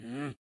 描述：Voz de personaje坟墓，没有领带palabras，独唱儿子sonidos de rabia。
Tag: Personaje VOX 圣马洛